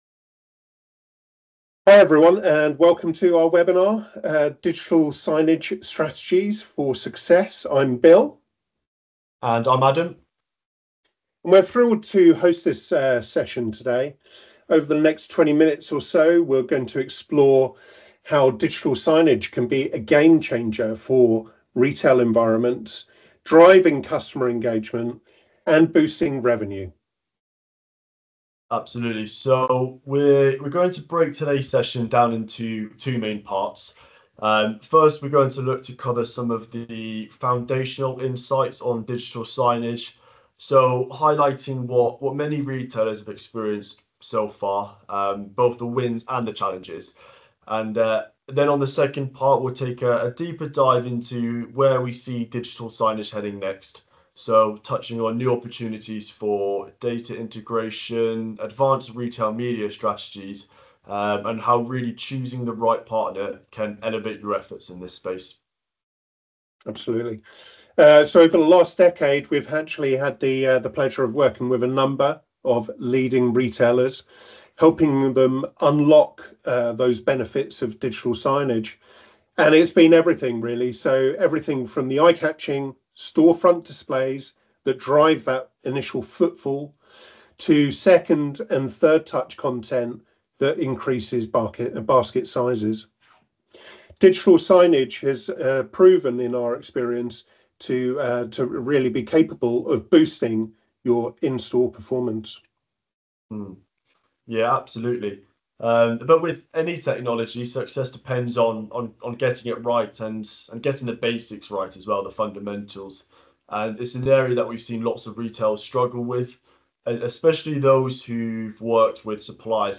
You can catch up on their discussion below. Listen to our experts delve deeper into where digital signage is heading, new opportunities and why having the right media partner matters.
Elevating-Retail-Experiences-Webinar-Audio-.m4a